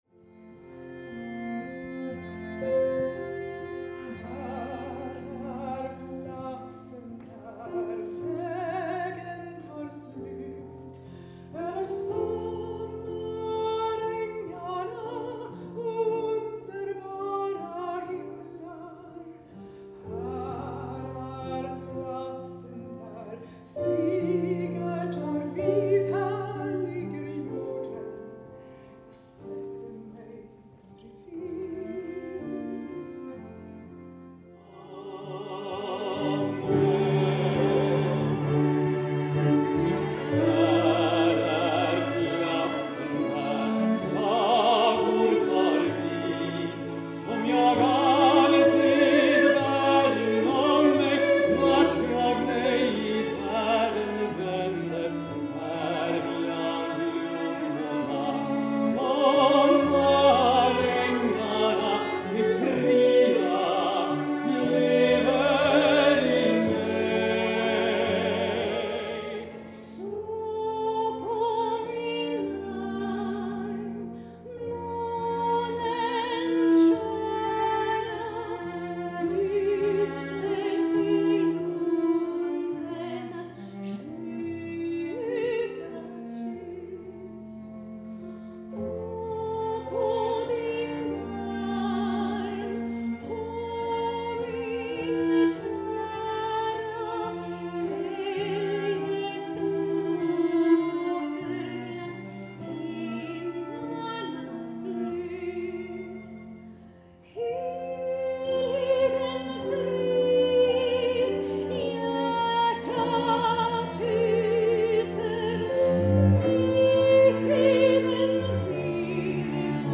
mixes opera, ballads, musical, pop and contemporary music
soprano
baryton
piano
violin
cello